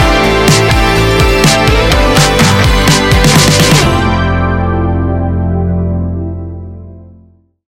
Ionian/Major
ambient
electronic
new age
chill out
downtempo
synth
pads
drone